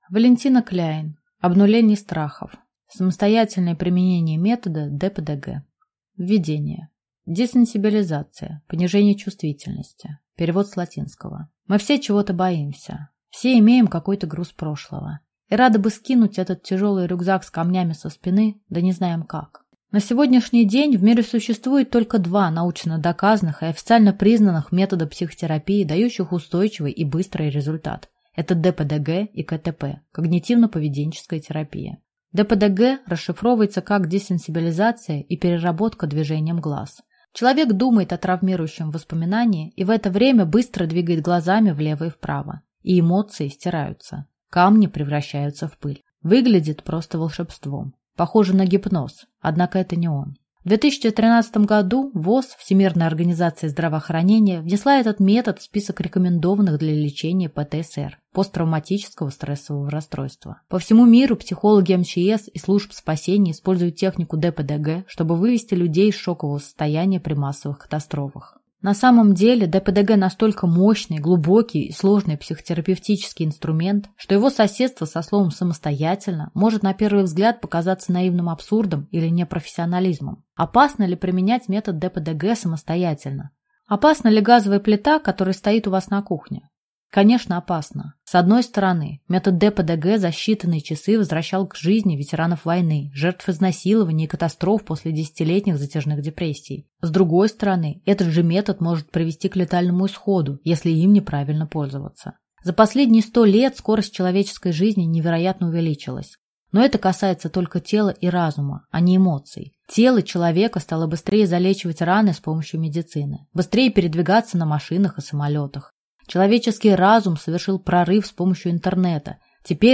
Аудиокнига Обнуление страхов. Самостоятельное применение метода ДПДГ | Библиотека аудиокниг